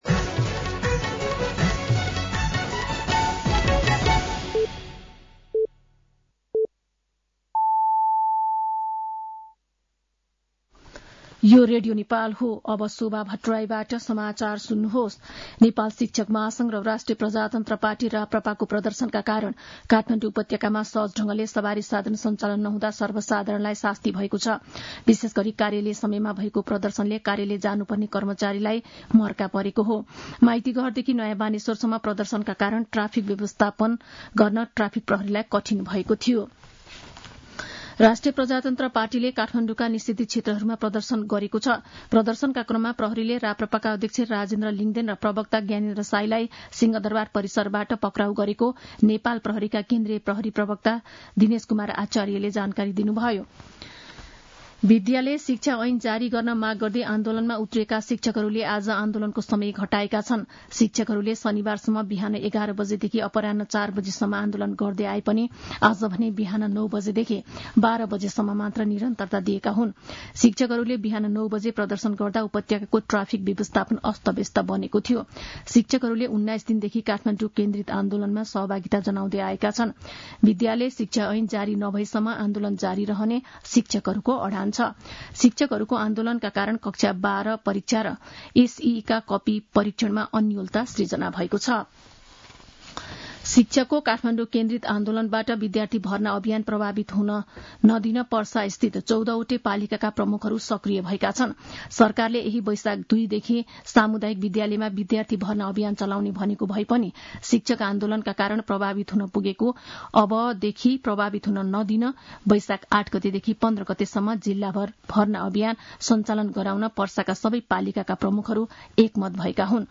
साँझ ५ बजेको नेपाली समाचार : ७ वैशाख , २०८२
5-pm-news1-7.mp3